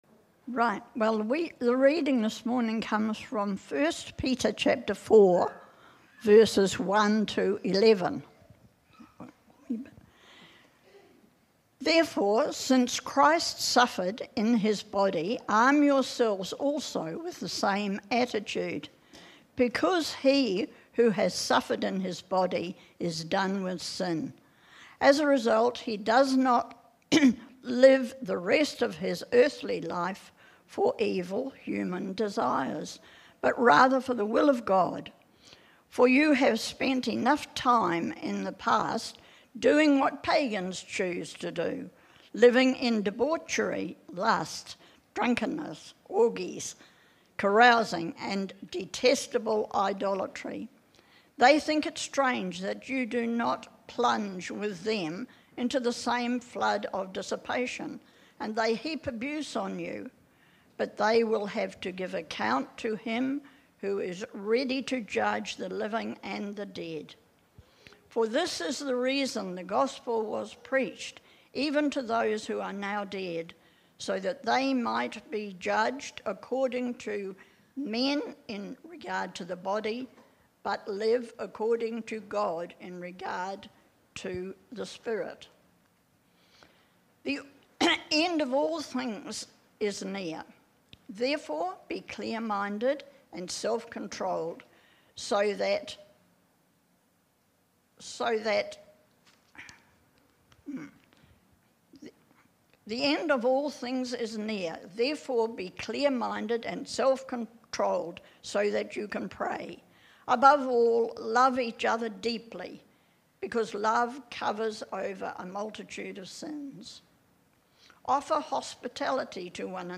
Sermons | Titirangi Baptist Church
Several times a year, we have one-off services celebrating God and what He is at work doing in our community.